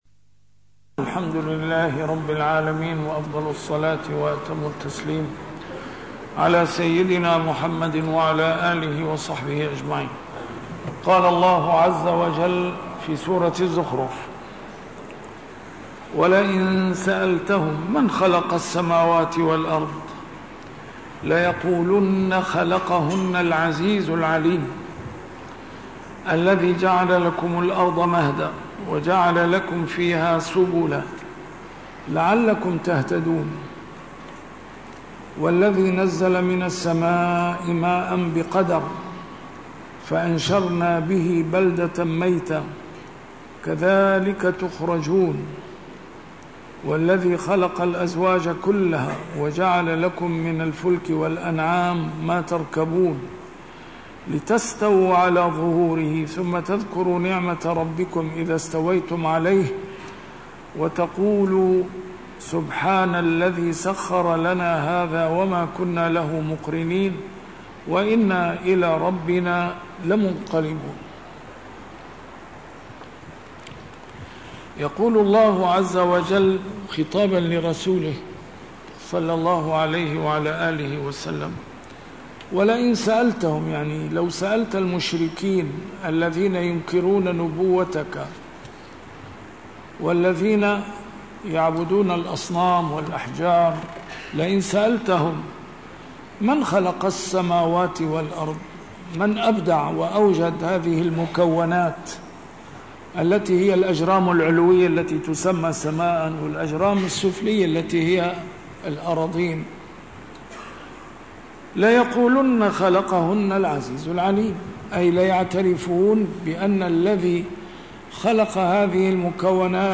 A MARTYR SCHOLAR: IMAM MUHAMMAD SAEED RAMADAN AL-BOUTI - الدروس العلمية - تفسير القرآن الكريم - تسجيل قديم - الد س 574: الزخرف 09-14